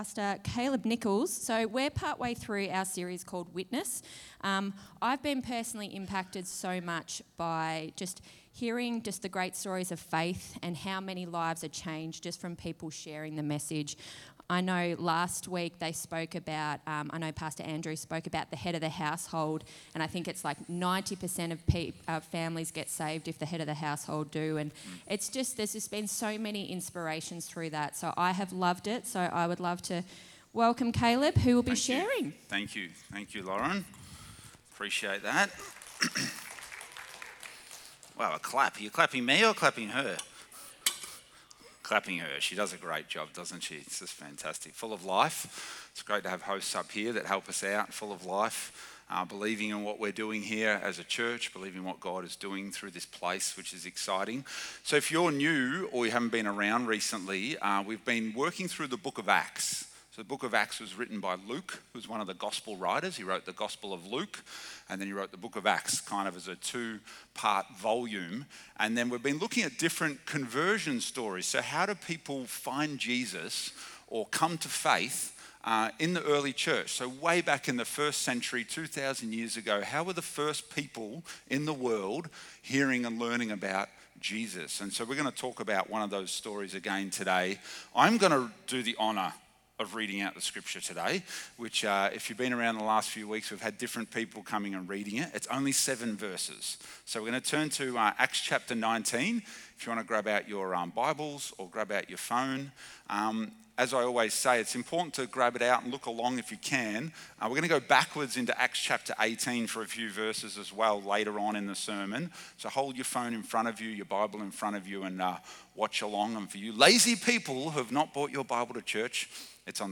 Sermon Notes John’s disciples